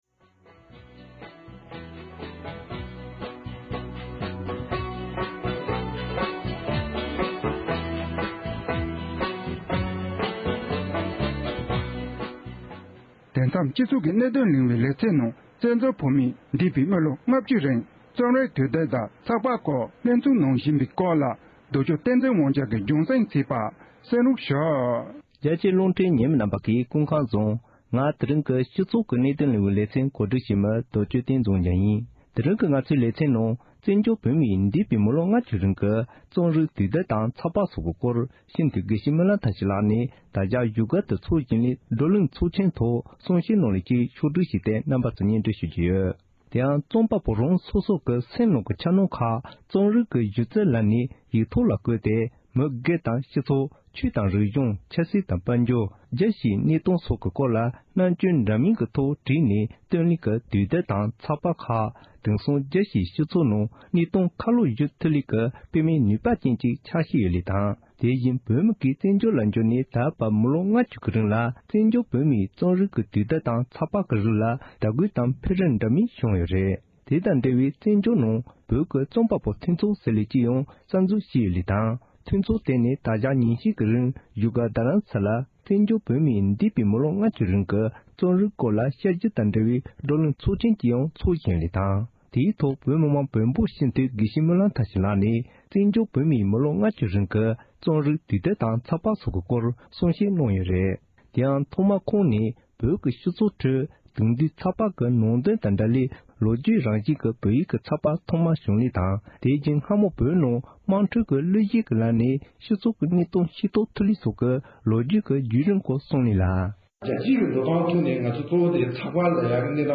སྤྱི་འཐུས་དགེ་བཤེས་སྨོན་ལམ་ཐར་ཕྱིན་ལགས་ཀྱིས་ད་ཆ་བཞུགས་སྒར་དུ་ཚོགས་བཞིན་པའི་བགྲོ་གླེང་ཚོགས་ཆེན་ཐོག་གསུངས་བ་ཞིག་